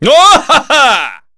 Chase-Vox_Happy4_kr.wav